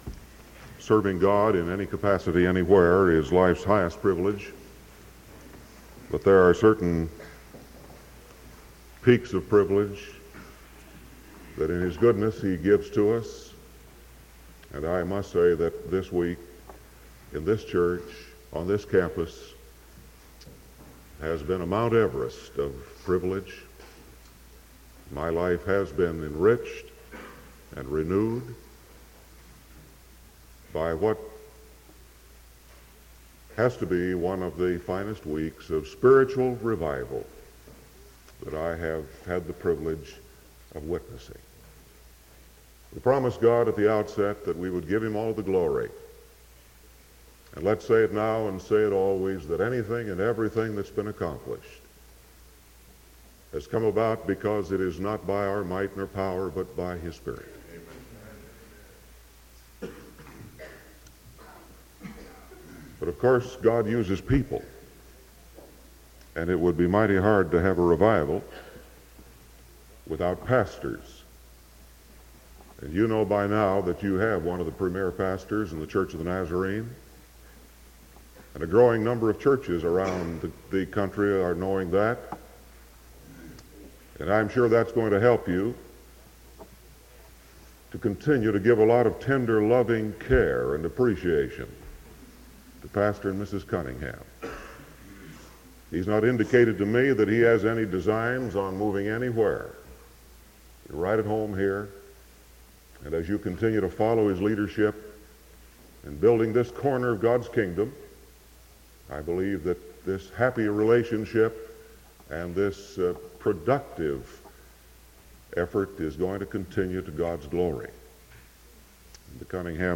Sermon March 2nd 1975 PM